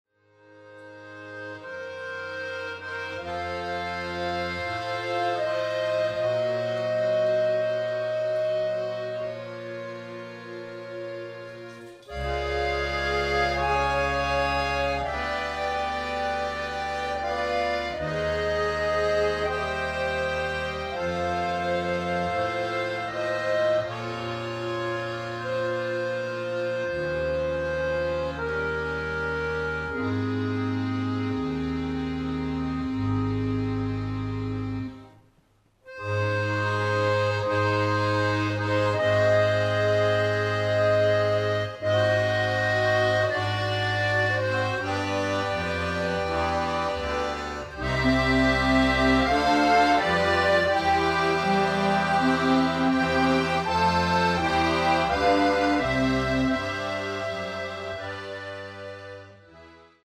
Schweizer Volksmusik, Spirituelle Musik
* Akkordeon (in unseren Breitengraden auch Handorgel genannt)
* Schlagzeug
* Perkussion
* Klavier